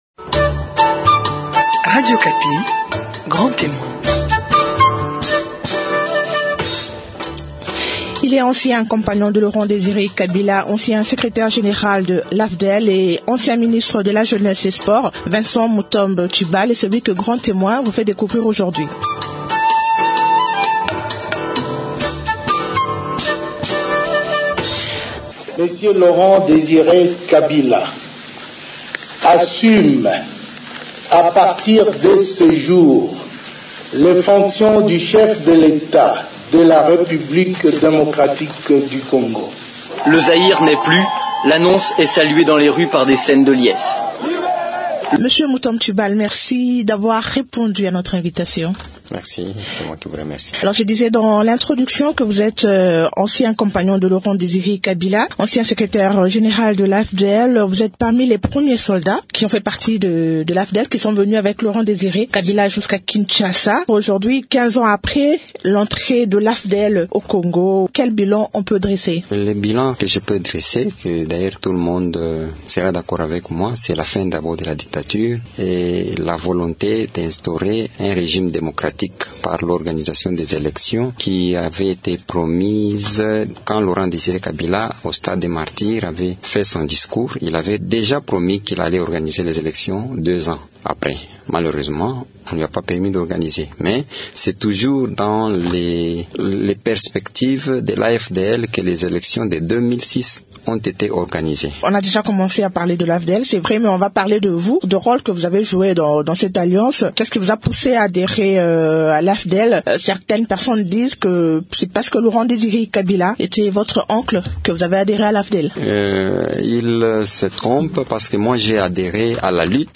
L’invité de Grand Témoin de ce dimanche 20 mai 2012 est Vincent Mutomb Tshibal, ancien compagnon de feu le président Laurent Désiré Kabila.